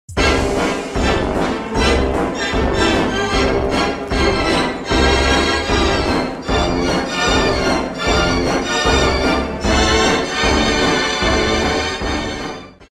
short, punchy audio clip